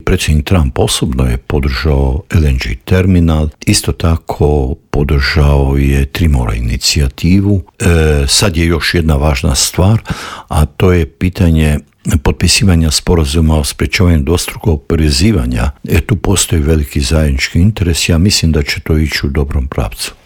ZAGREB - Dinamična politička zbivanja u svijetu tema su i novog Intervjua Media servisa. S bivšim ministrom vanjskih poslova Matom Granićem, prošli smo atlas tražeći odgovore na to kako će Trumpova vladavina odjeknuti izvan granica SAD-a, a razgovarali smo i o krizi vlasti u Njemačkoj.